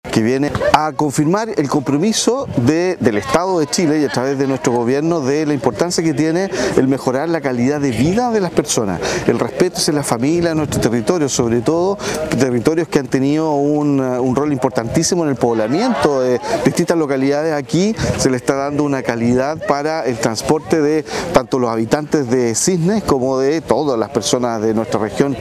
Por su parte, el delegado presidencial regional, Rodrigo Araya, mencionó que esta obra “viene a confirmar el compromiso del Estado y de nuestro Gobierno por mejorar la calidad de vida de las personas, sobre todo en territorios que han tenido un rol importantísimo en el poblamiento de la región”, aseguró.
Audio-cuna-3-Delegado.mp3